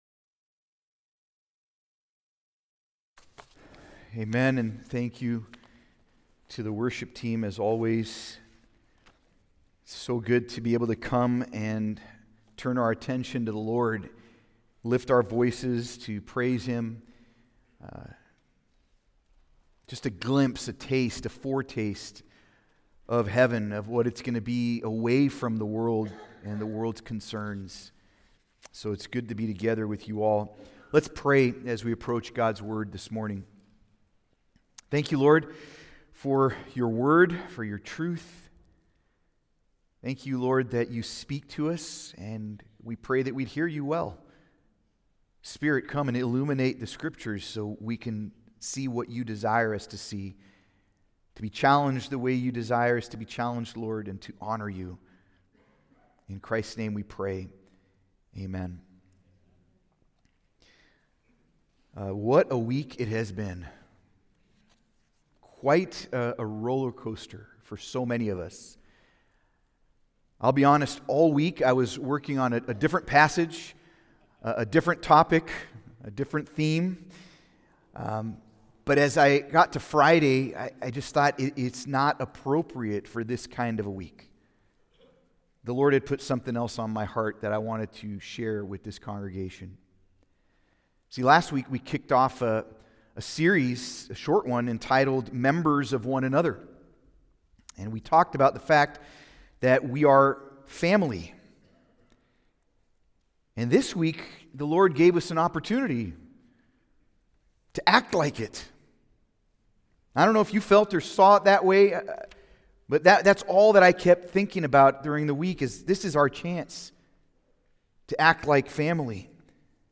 Sermon
Service Type: Sunday Service